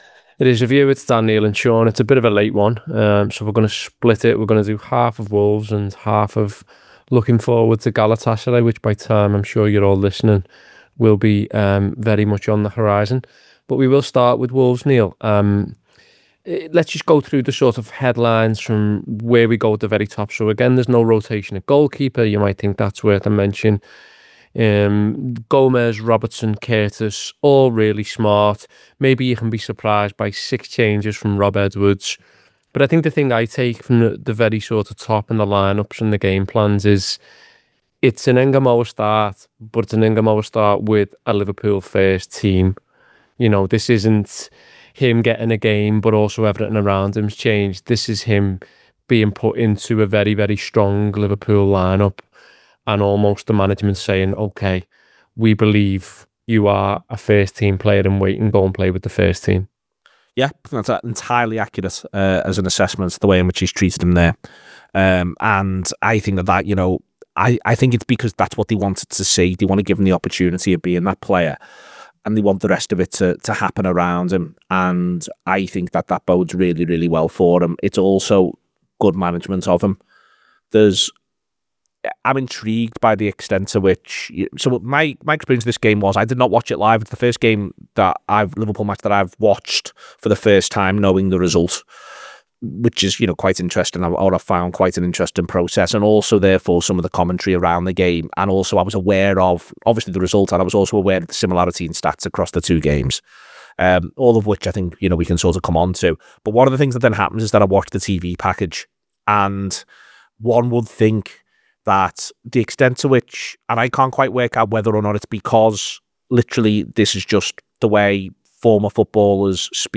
Below is a clip from the show – subscribe to The Anfield Wrap for more review chat…